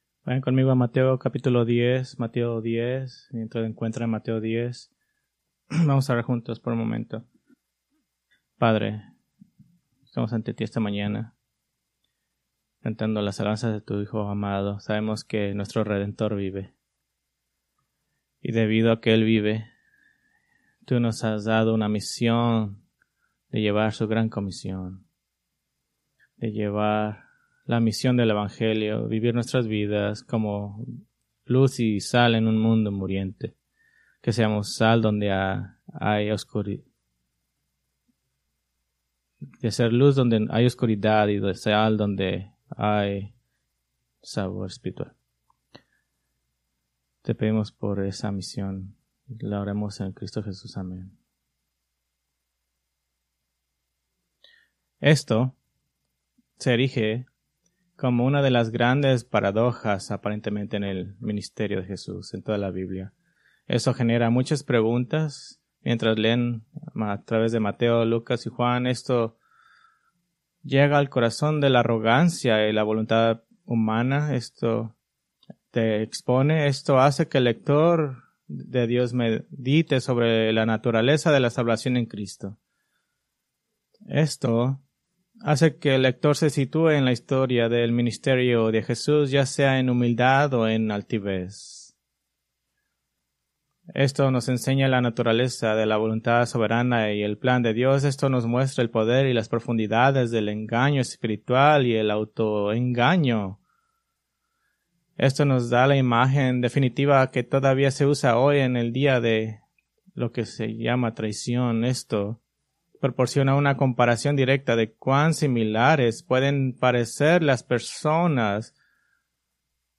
Preached April 6, 2025 from Mateo 10:4